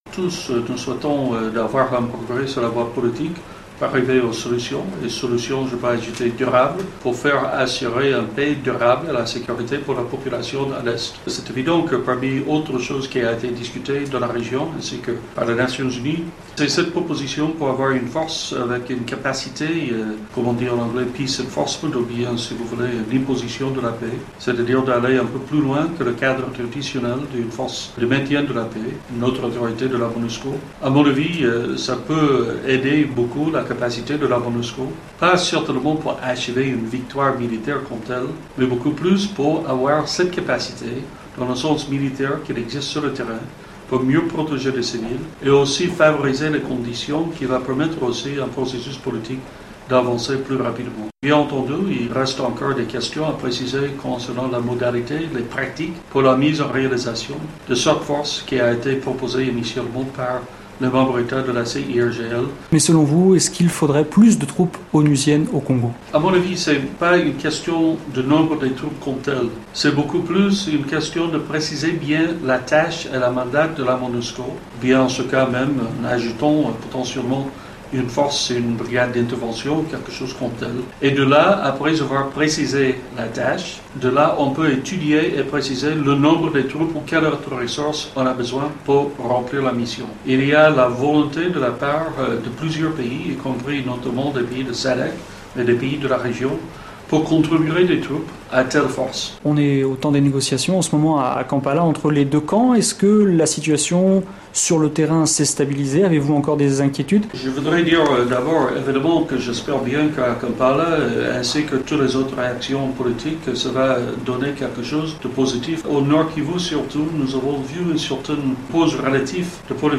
interviewé à Kinshasa